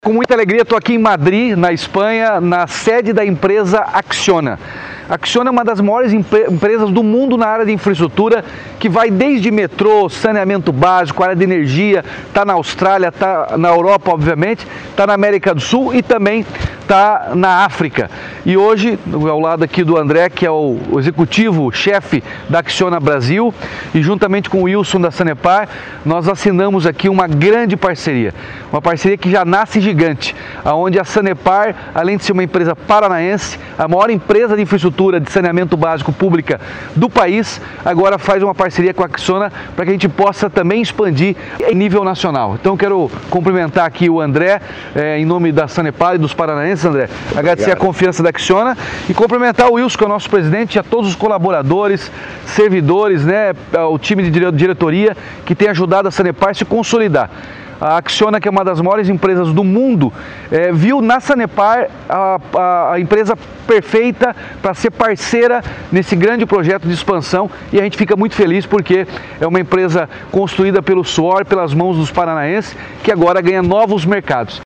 Sonora do governador Ratinho Junior sobre a parceria da Sanepar com a Acciona